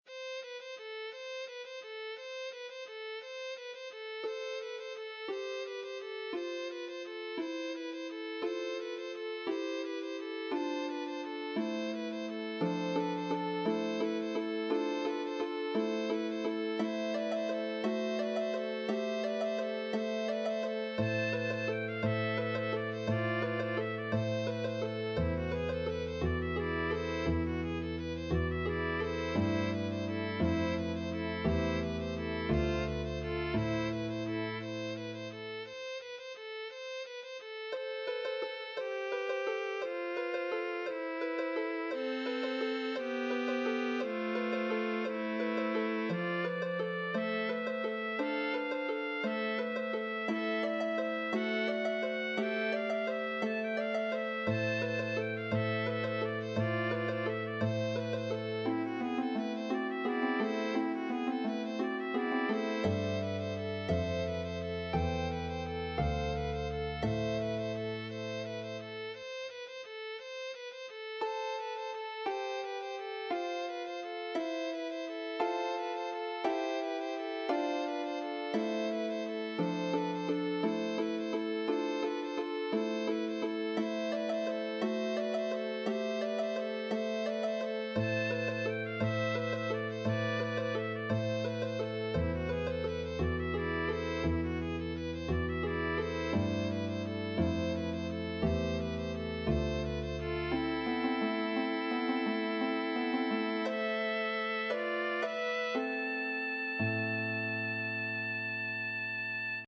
(harp and violin, viola or flute)